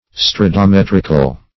Search Result for " stradometrical" : The Collaborative International Dictionary of English v.0.48: Stradometrical \Strad`o*met"ric*al\, a. [It. strada street or road + E. metrical.]